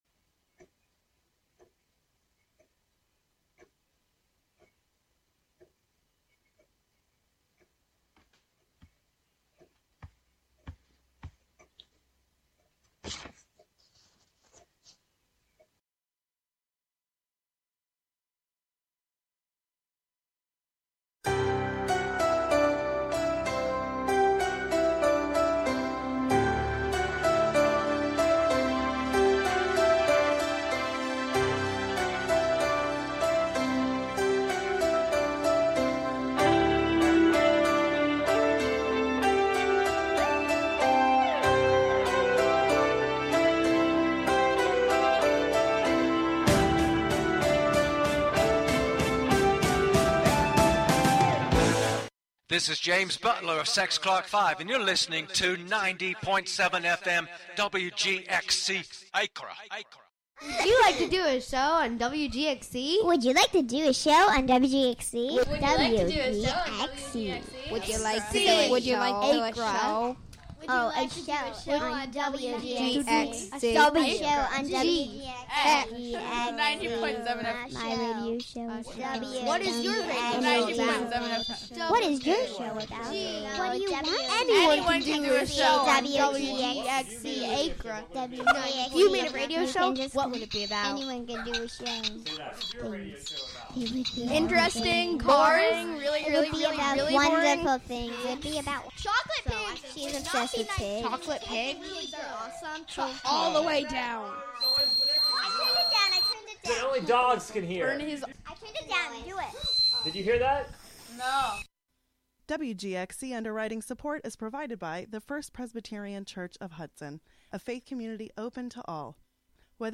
On this monthly program, “La Ville Inhumaine” (The Inhuman City), you will hear music, found sounds, words, intentional noise, field recordings: altogether, all at once.